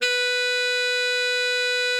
bari_sax_071.wav